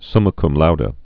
(smə km loudə, -dā, -dē, sŭmə kŭm lôdē)